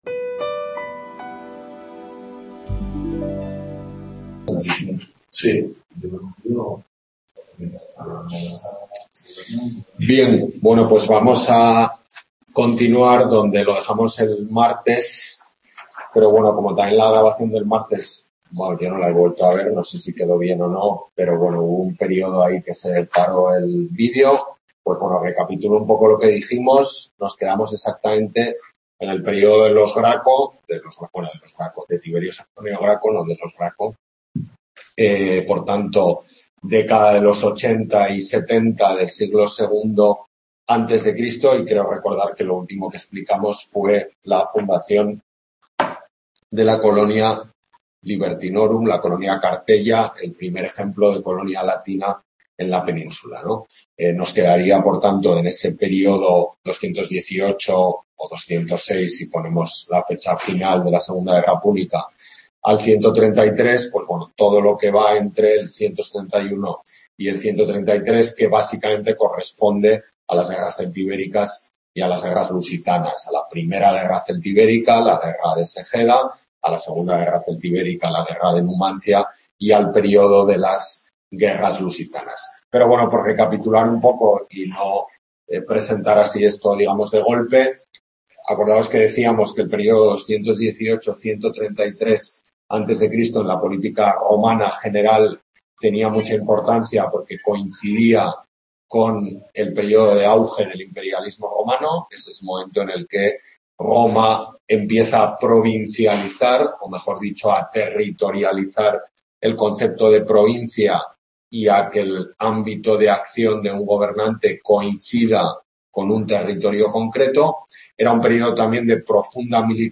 Tutoría de Hª Antigua de la Península Ibérica en la UNED de Tudela